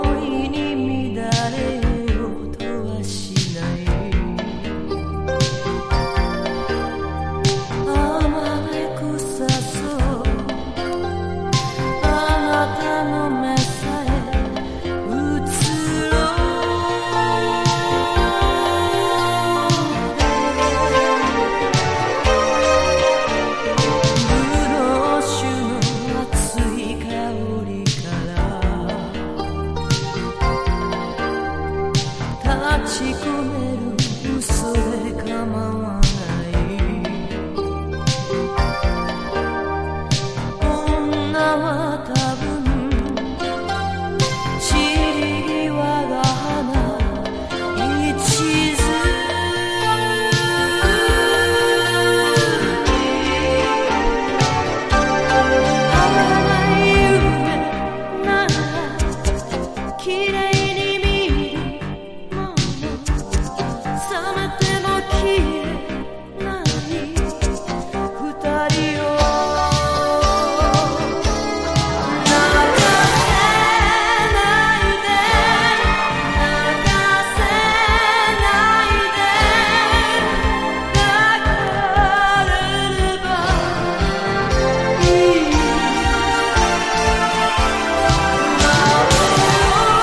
和レアリック・バラードA2や80sアーバン・エレクトロなA3など◎です。